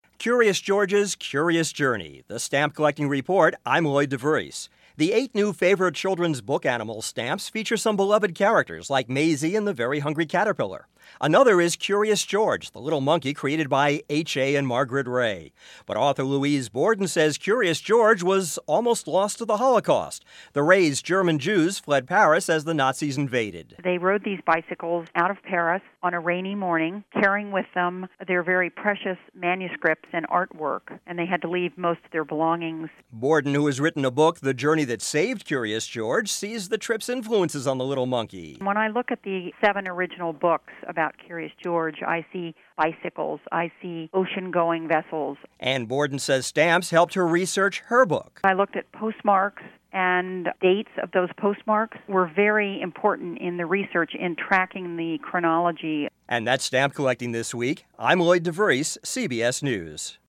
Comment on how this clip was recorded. For broadcast on CBS Radio Network stations January 14-15, 2006: